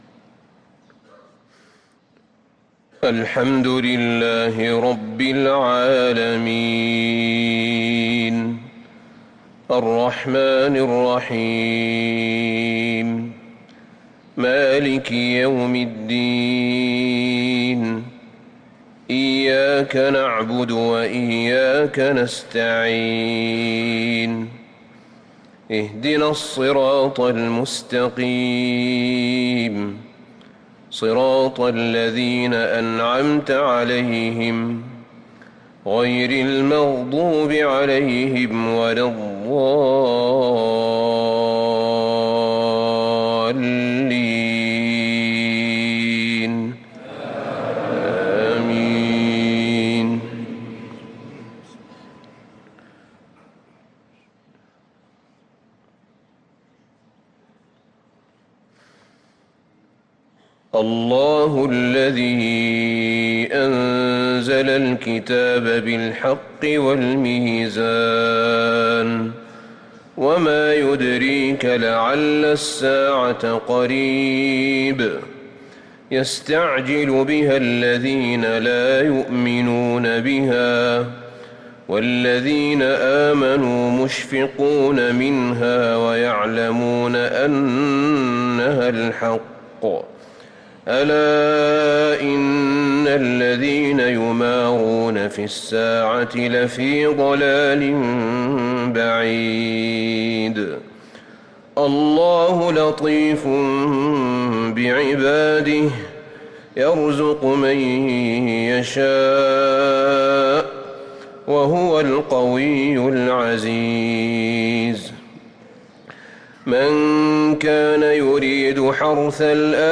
صلاة الفجر للقارئ أحمد بن طالب حميد 9 ربيع الأول 1445 هـ
تِلَاوَات الْحَرَمَيْن .